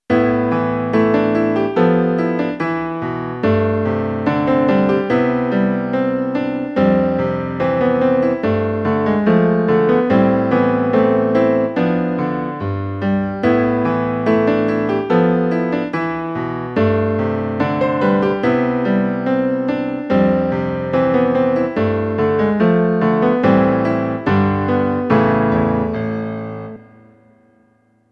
比較しやすいよう、通常の三度の積み上げでも伴奏を作っていますので、響きやイメージの違いを確認してみてください。
作例１（ジブリ風コード進行）